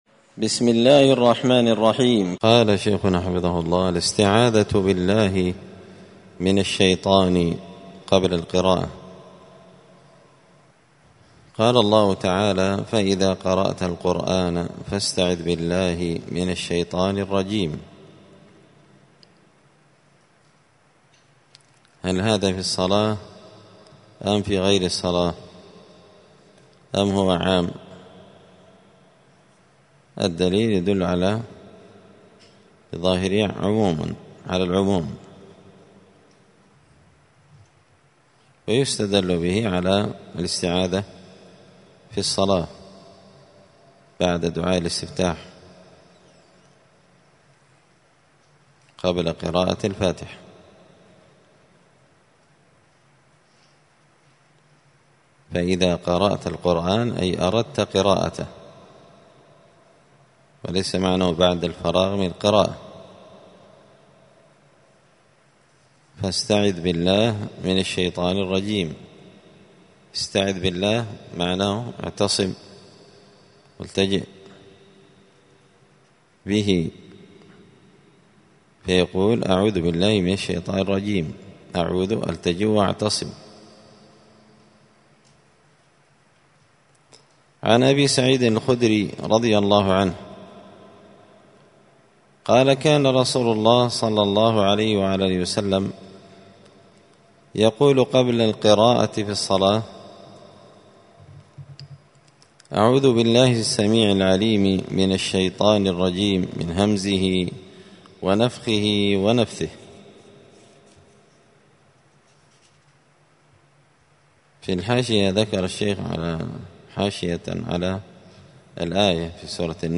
*{الدرس السادس والعشرون (26) أذكار الصلاة الاستعاذة بالله من الشيطان قبل القراءة}*